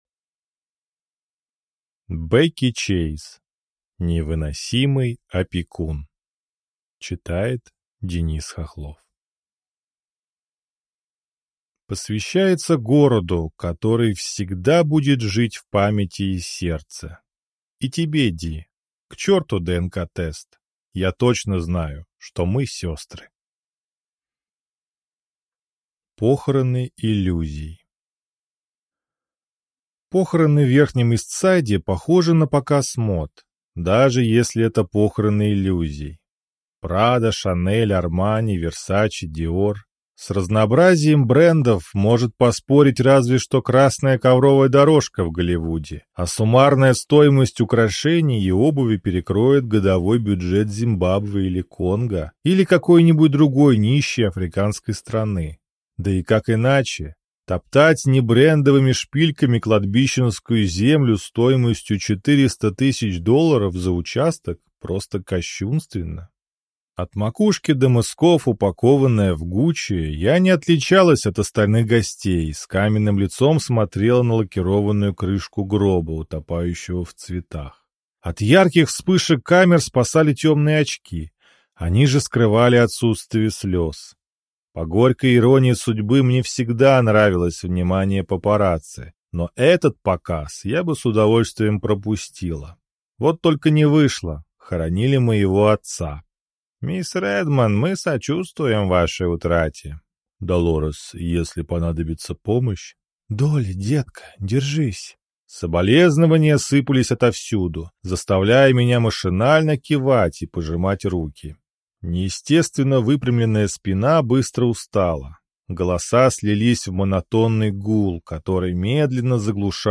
Аудиокнига Невыносимый опекун | Библиотека аудиокниг